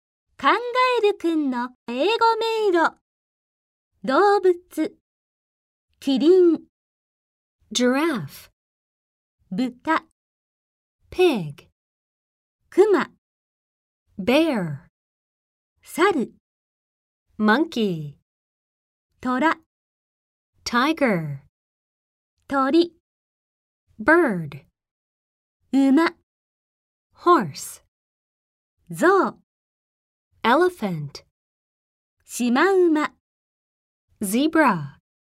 ネイティブスピーカーによる発音でお聞きいただけます。